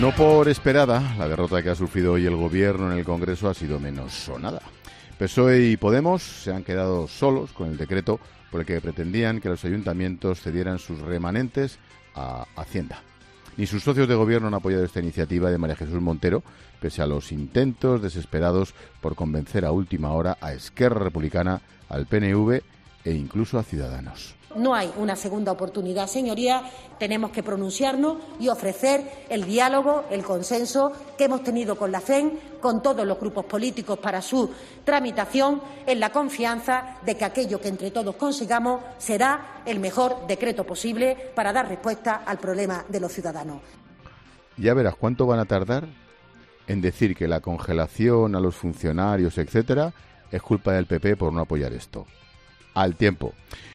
El presentador de 'La Linterna' se lanza a augurar lo que hará el Gobierno con los funcionarios
Ángel Expósito ha aprovechado su monólogo inicial de este jueves para valorar uno de los grandes temas que ha dejado la actualidad del día: la negativa del Congreso a dar luz verde a la iniciativa del Gobierno sobre los remanentes municipales.